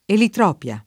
eliotropio [elLotr0pLo] (antiq. o poet. elitropio [elitr0pLo]) s. m.; pl. ‑pi (raro, alla lat., ‑pii) — nell’uso ant., elitropia [